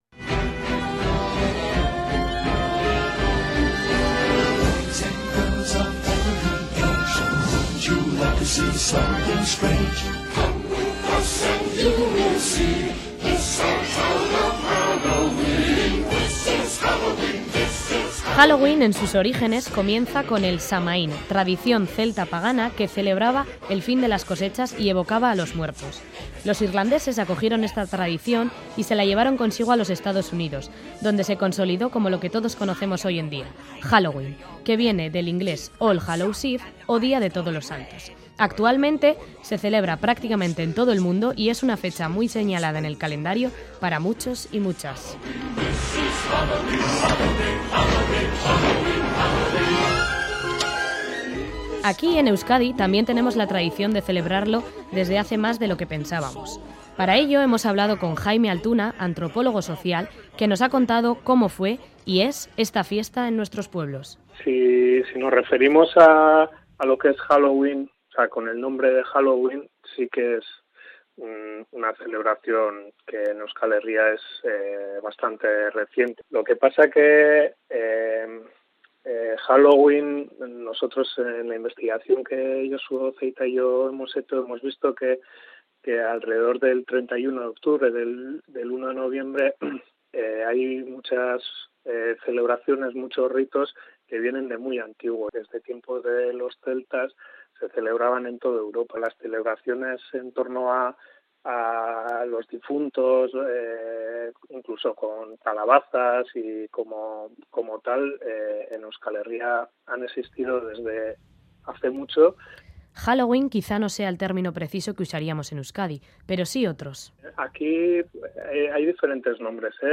Reportaje entorno al pasado y presente de estas ancestrales tradiciones dentro y fuera de Euskadi